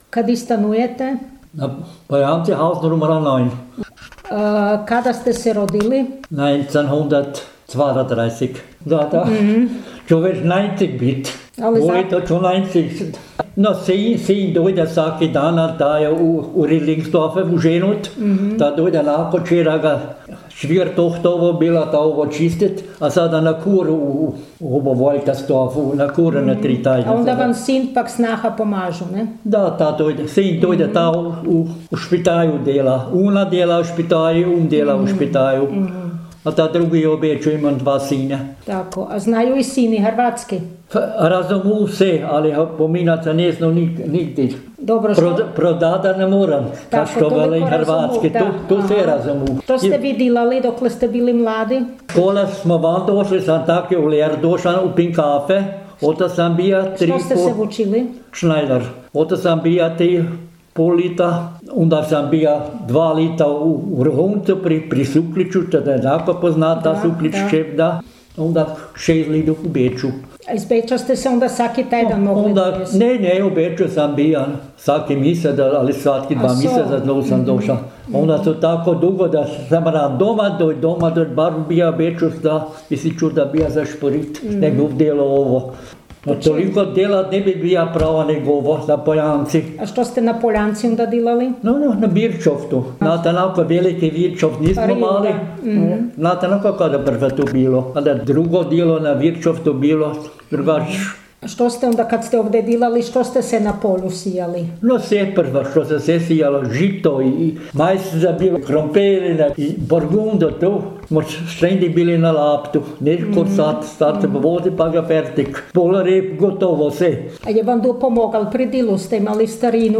jezik naš, jezik naš gh dijalekti
Poljanci – Govor – Odrasti u selu, Beč
Poljanci_govor-2.mp3